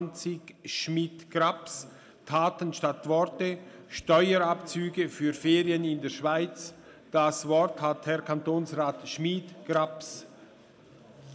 13.6.2019Wortmeldung
Session des Kantonsrates vom 11. bis 13. Juni 2019